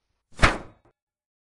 中世纪的战斗 " 盾牌打击3
用Tascam记录金属光栅的声音。
Tag: 中世纪 庆典 罢工 棉絮乐 金属 盾击 冲击